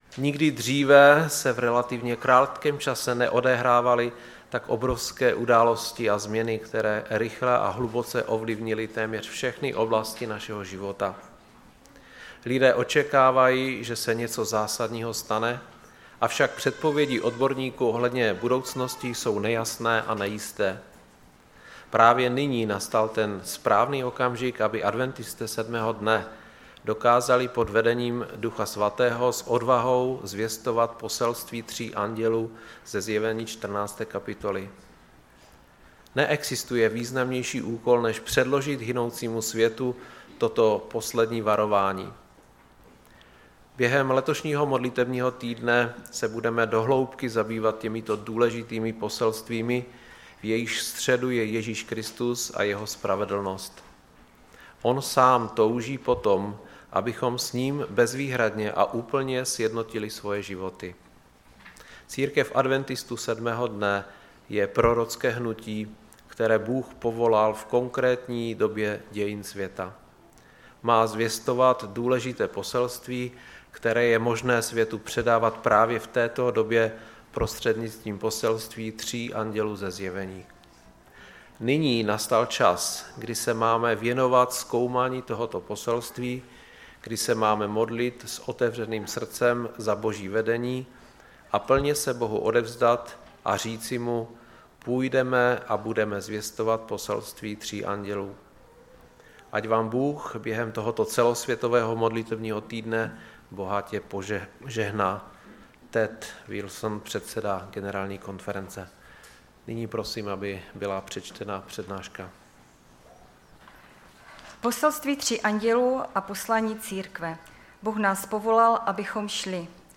Sbor Ostrava-Radvanice. Shrnutí přednášky začíná ve 14:30.